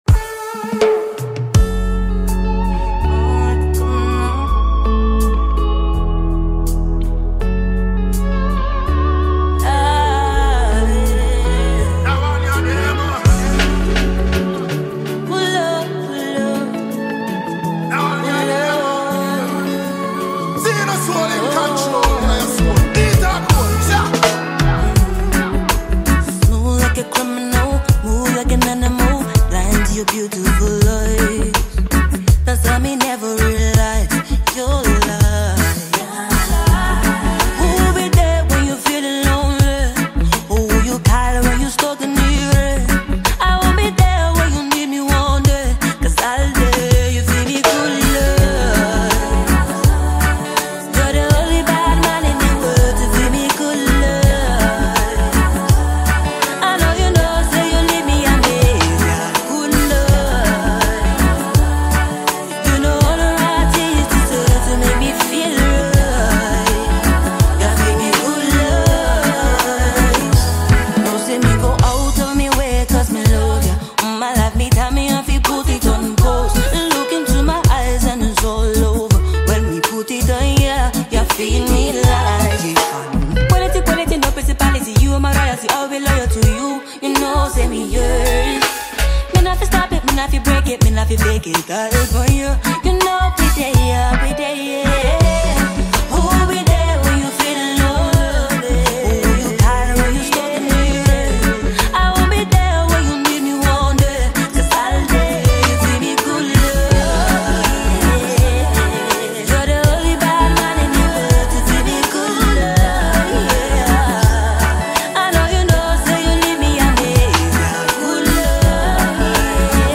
Ghanaian female singer and songwriter